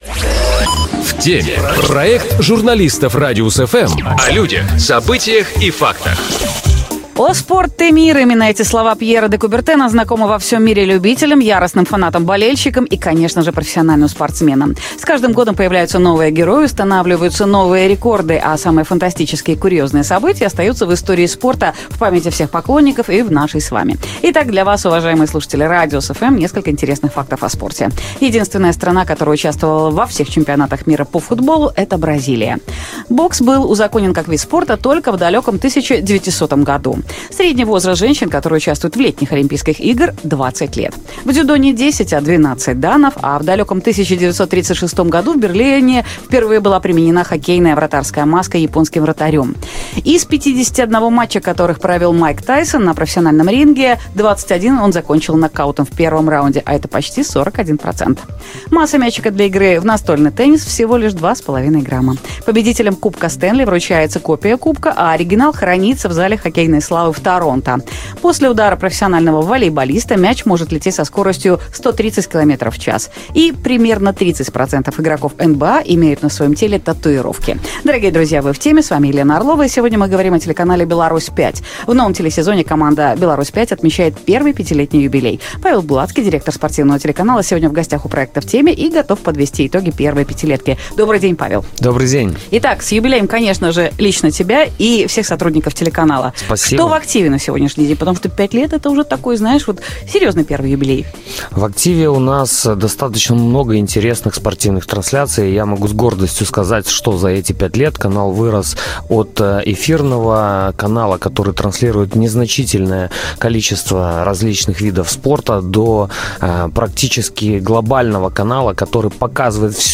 У нас в гостях